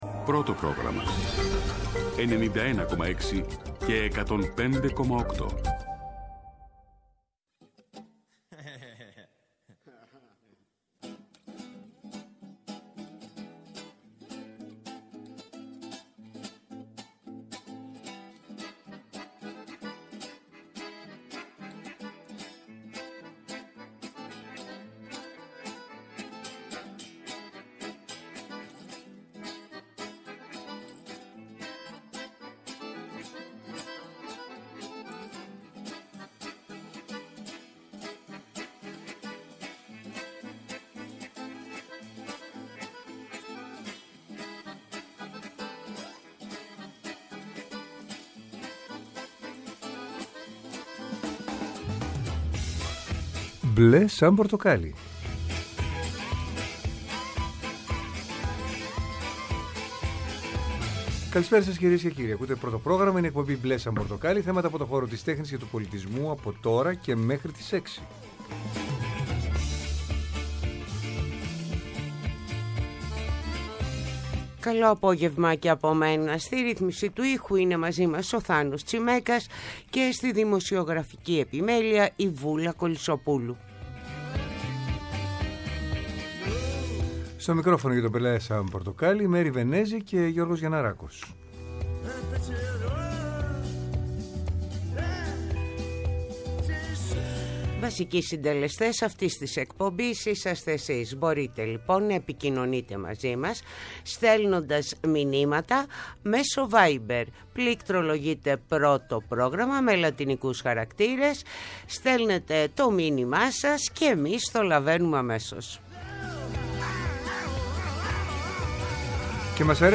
Καλεσμένοι μας τηλεφωνικά